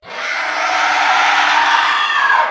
falling_skull1.ogg